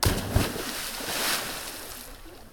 water-splash-2.ogg